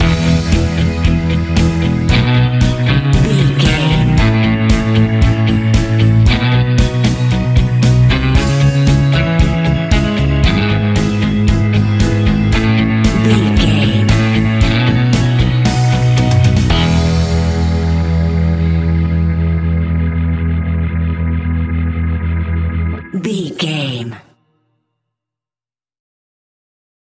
Epic / Action
Fast paced
Ionian/Major
pop rock
indie pop
fun
energetic
uplifting
acoustic guitars
drums
bass guitar
electric guitar
piano
organ